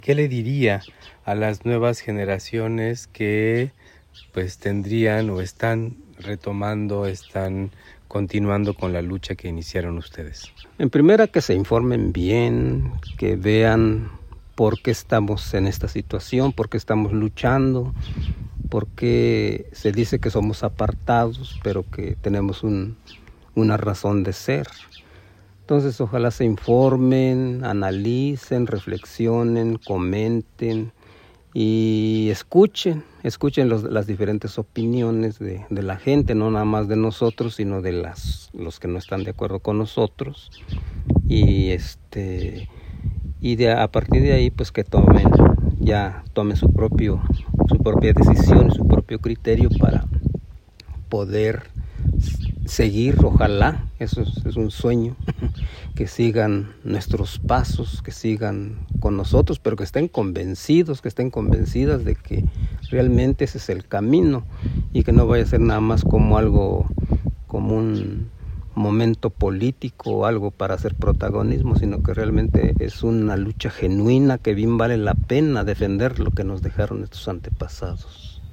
En entrevista